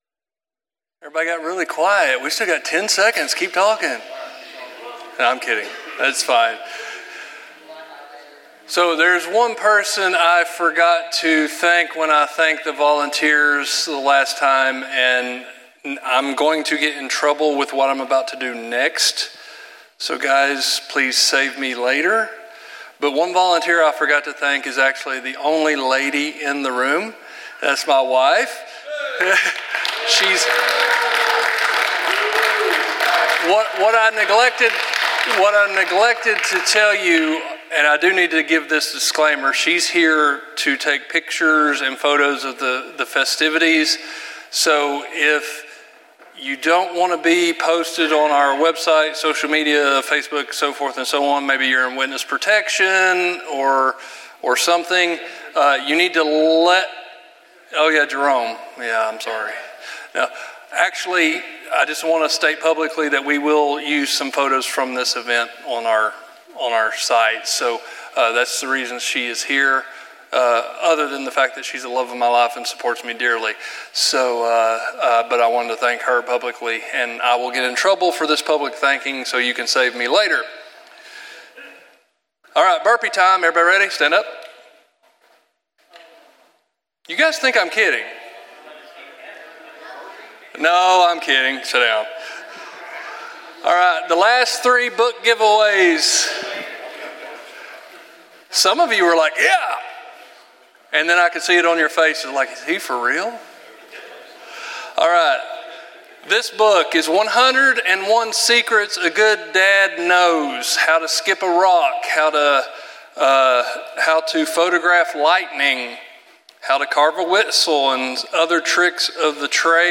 Session 2 of our Men's Conference from December 2025.